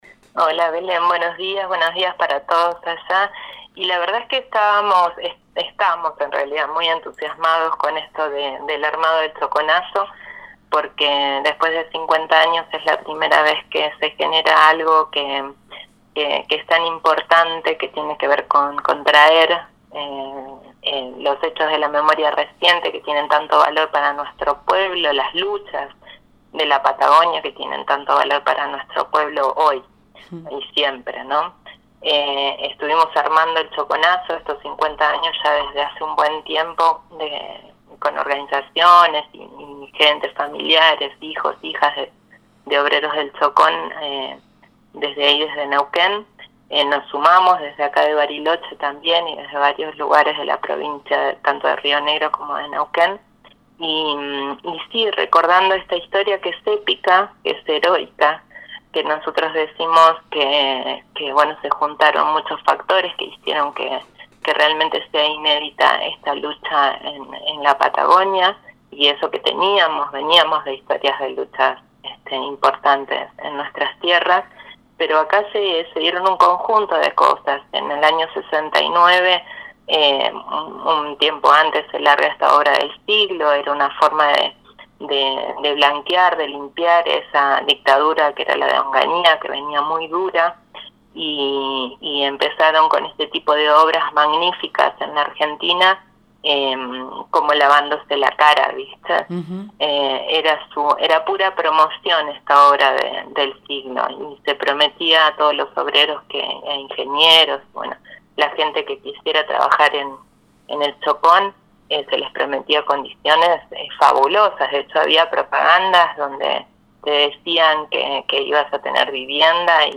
En diálogo con Proyecto Erre contó qué significó esa gesta de trabajadores que construían la represa de El Chocón en pésimas condiciones laborales, mientras batallaban contra la dictadura de Onganía y la burocracia sindical de aquel entonces.